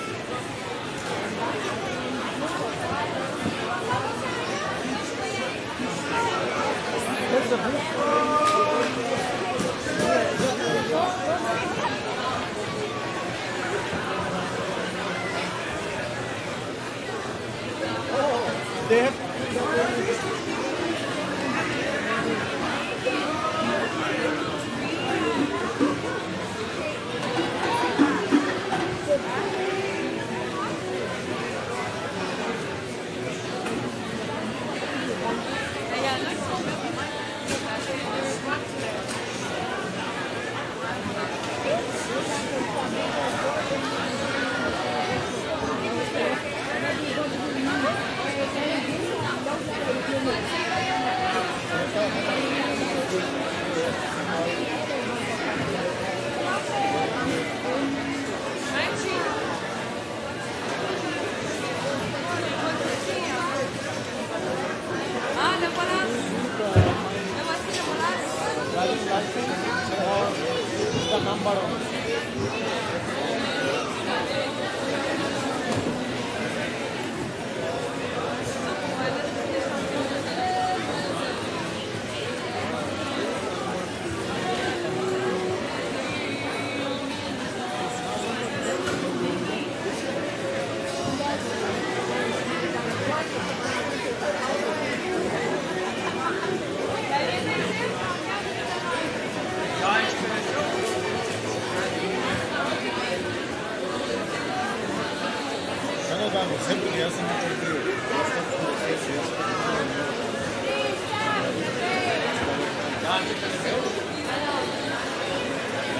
random » theater crew stage setup voices quebecois and mandarin1
描述：theater crew stage setup voices quebecois and mandarin1.flac
标签： stage quebecois crew mandarin voices setup theater
声道立体声